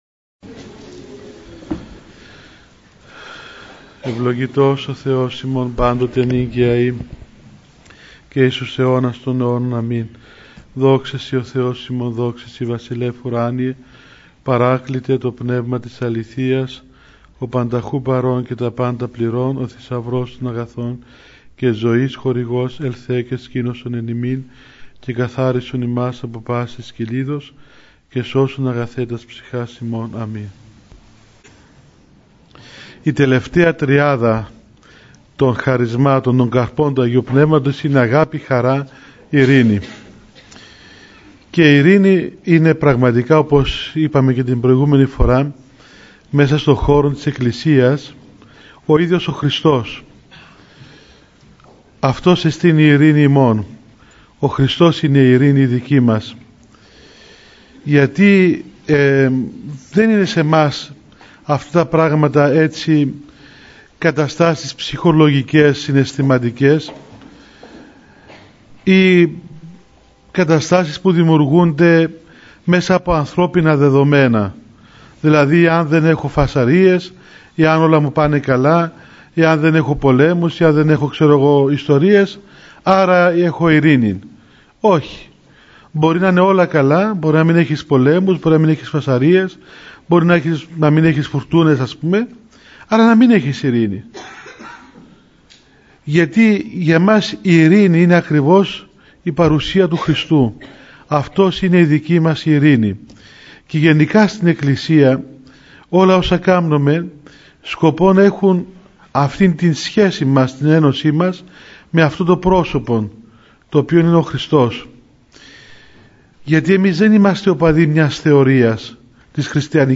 1138 Η εγκράτεια 📄💬🔖 – Ομιλίες A' Μητροπολίτη Λεμεσού Αθανάσιου – Lyssna här